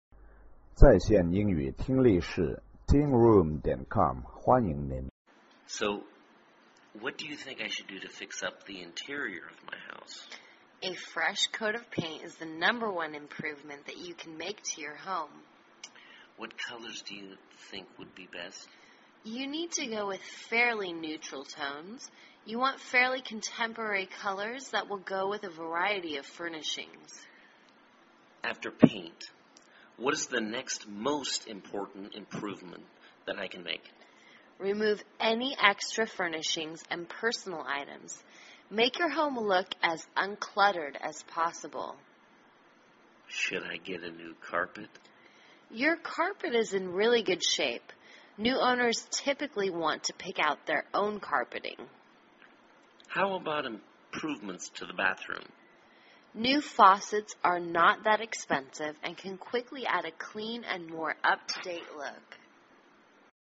卖房英语对话-Fixing Up the Interior(1) 听力文件下载—在线英语听力室